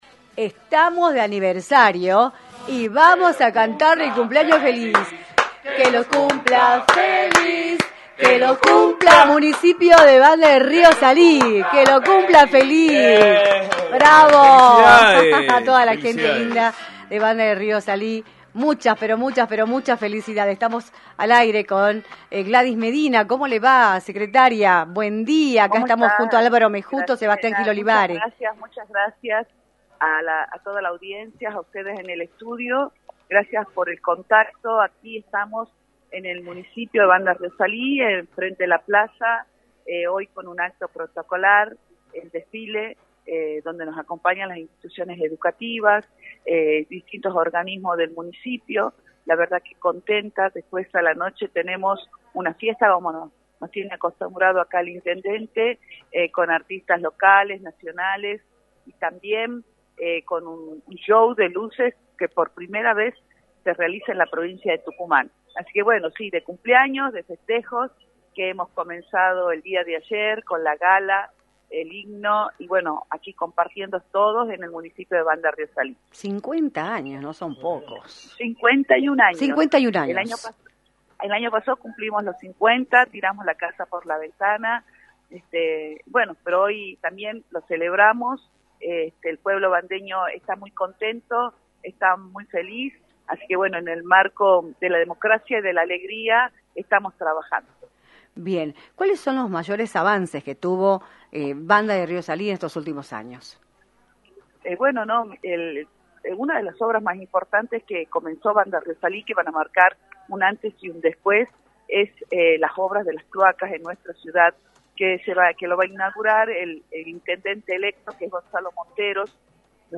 Gladys Medina, Secretaria de Atención a Familias en Riesgo Social y candidata a Diputada Nacional, informó en “Libertad de Expresión”, por la 106.9, las actividades que tiene preparada la Municipalidad de La Banda del Río Salí por el aniversario número 51 del Municipio.